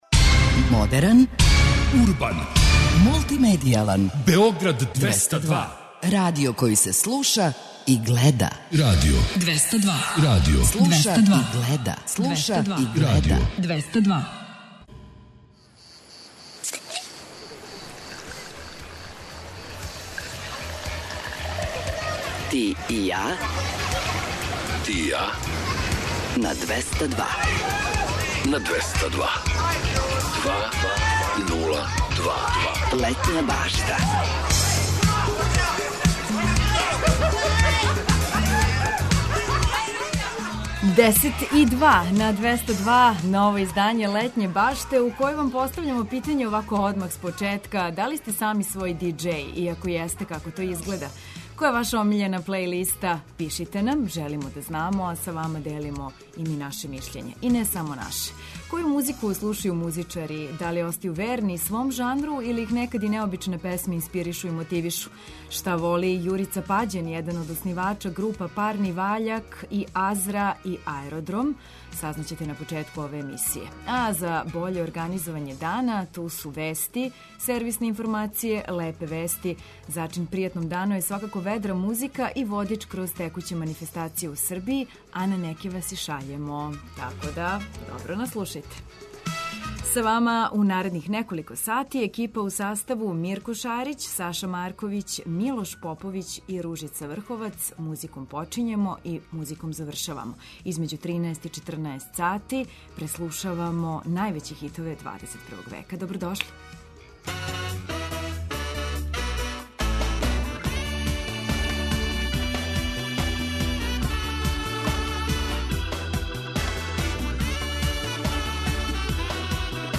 Зачин пријатном дану биће ведра музика, лепе вести, водич кроз текуће манифестације у Србији, а на неке вас и шаљемо!